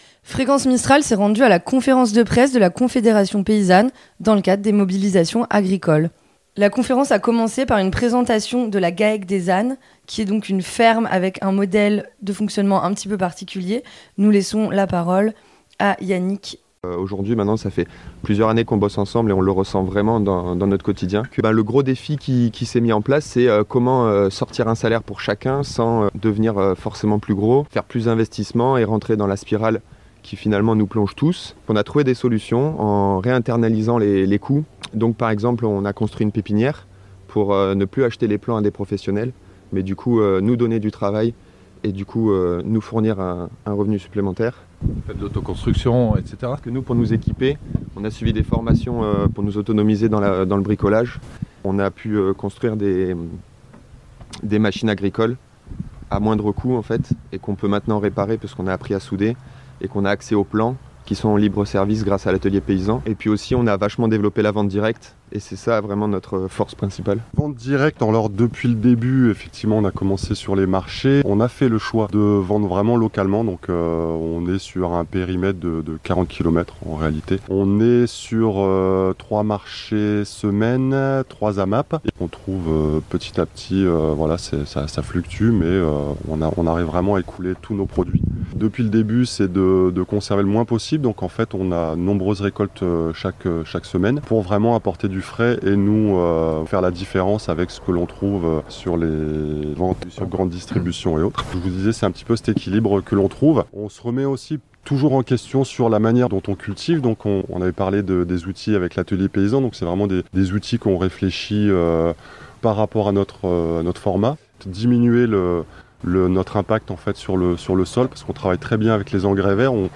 Conférence de presse - Confédération paysanne
Le 30 janvier se tenait la conférence presse de la Confédération Paysanne 04 afin d’évoquer la situation du monde en France, en Europe et aussi dans les Alpes de Haute Provence. Celle ci s’est déroulée sur une ferme des Mées, le Gaec du Champ des Anes, un exemple du modèle d’agriculture défendue par le syndicat : L’agriculture paysanne, une agriculture résiliente, autonome, proche des citoyens et prévue pour durer.